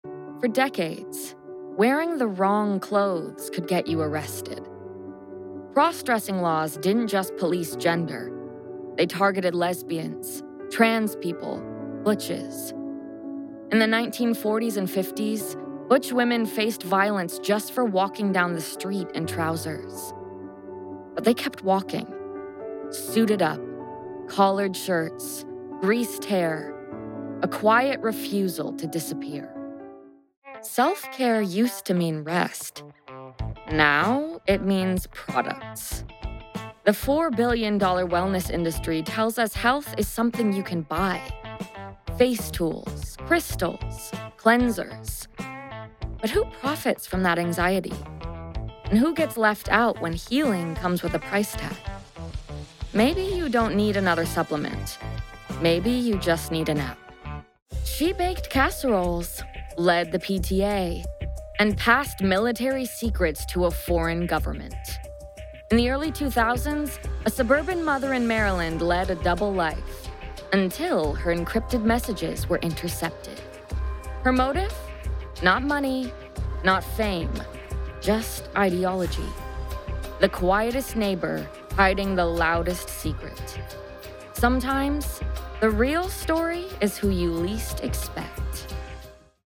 Audiobook Reel
• Native Accent: Californian
• Home Studio